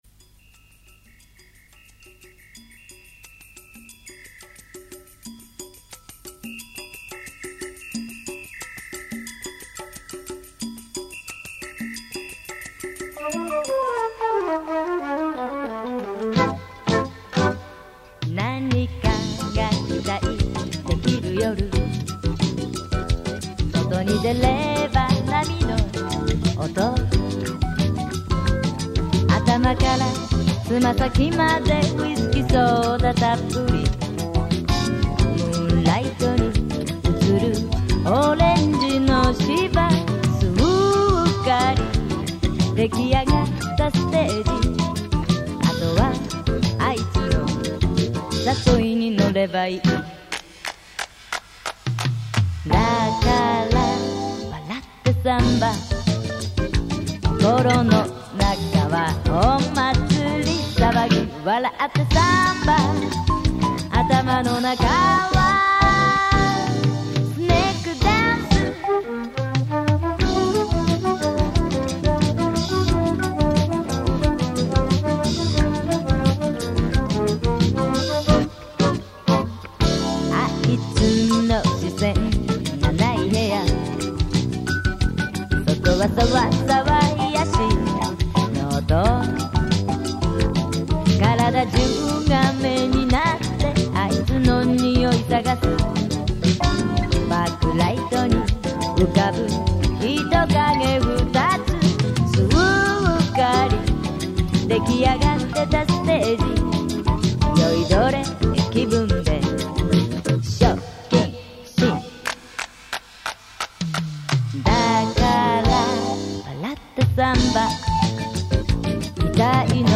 音源は、ミュージックテープで、特有のノイズが載っていますが、　一度聞くと耳に残る事でしょう。
動画のない場合、ミュージックテープから採取した音源を使用